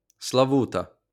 Slavuta (Ukrainian: Славута, IPA: [slɐˈʋutɐ]